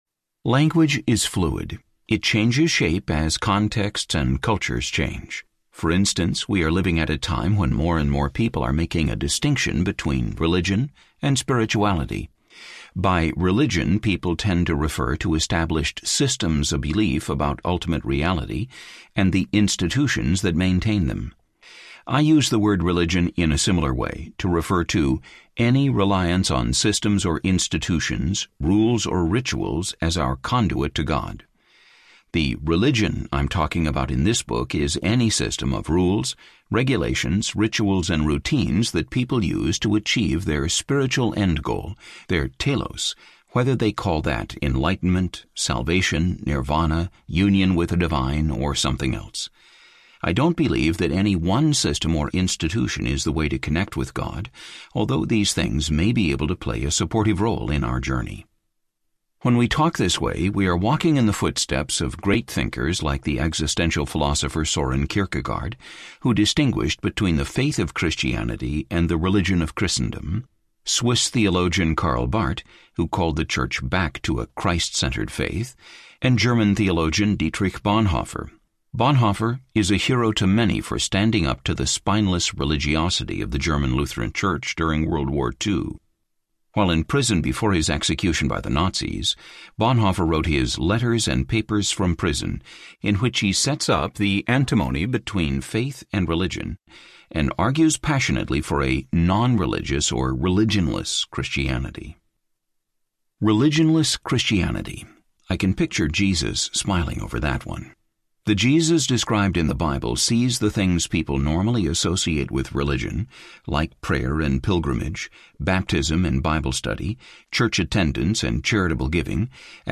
The End of Religion Audiobook
Narrator
6.4 Hrs. – Unabridged